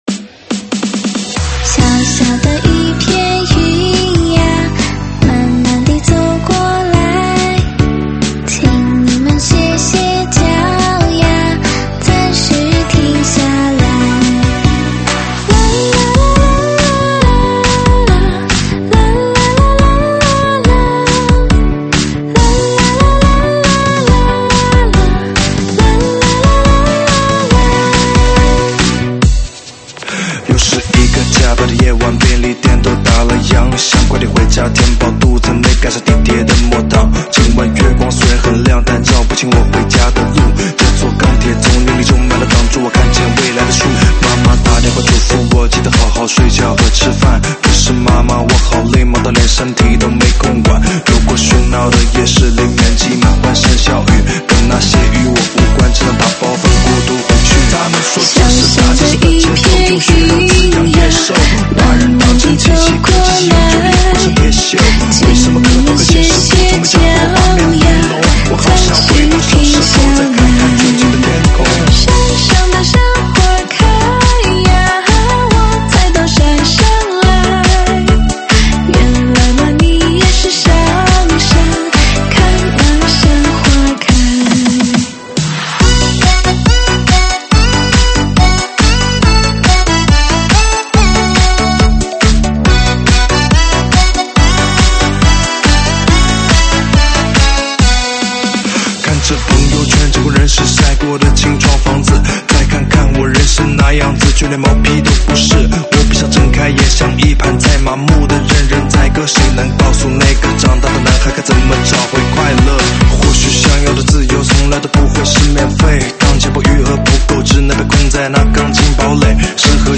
舞曲类别：中文Club